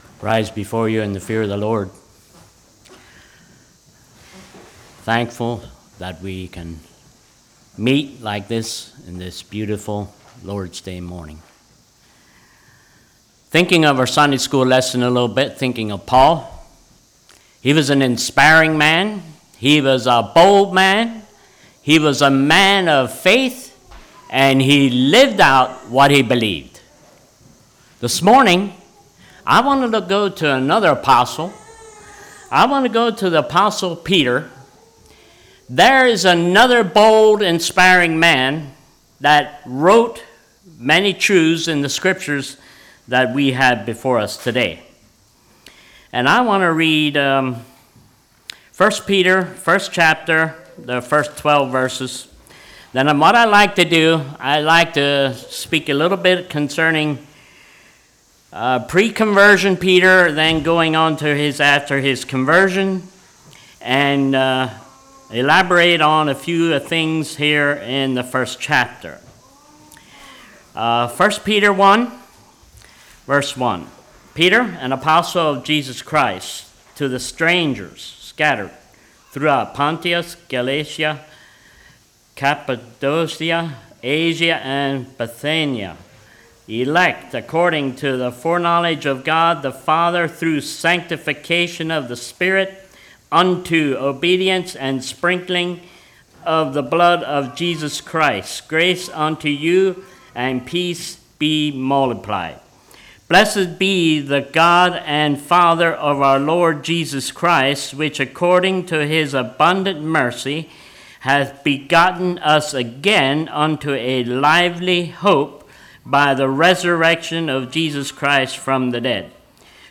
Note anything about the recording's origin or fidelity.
Congregation: Leola